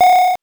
powerup_13.wav